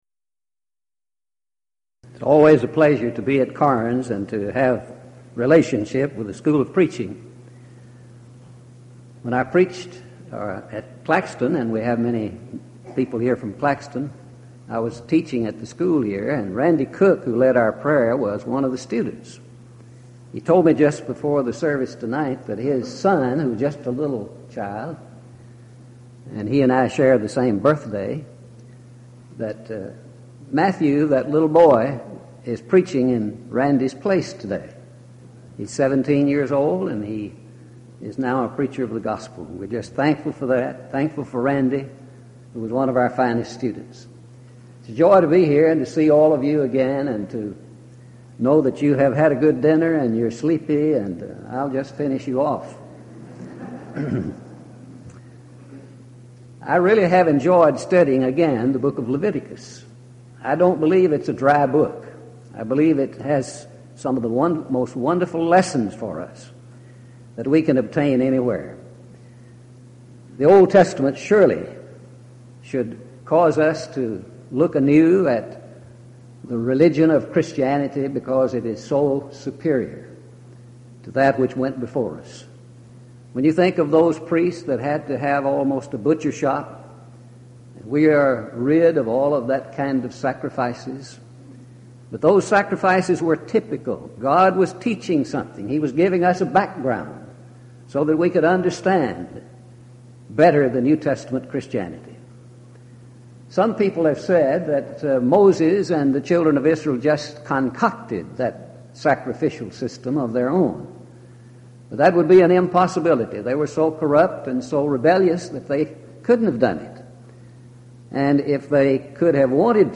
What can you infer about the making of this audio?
1998 East Tennessee School of Preaching Lectures